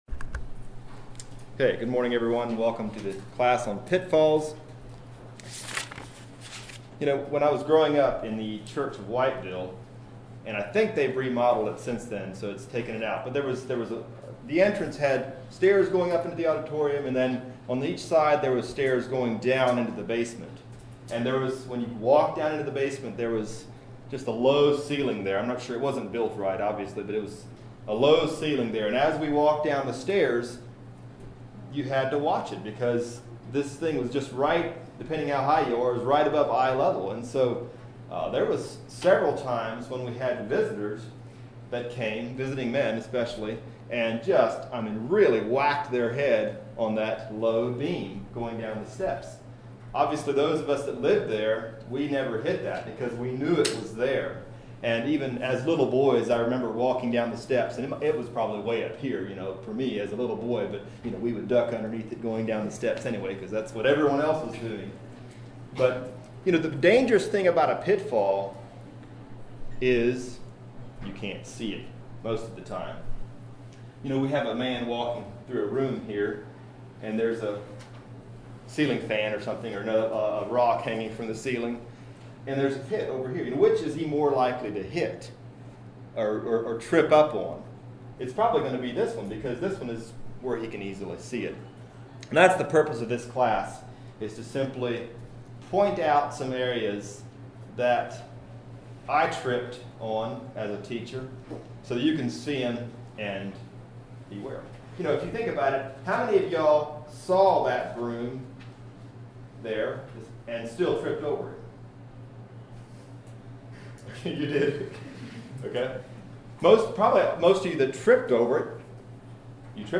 2025 Western Fellowship Teachers Institute 2024 Pitfalls 00:00